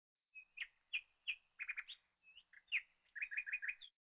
Bird 3.wav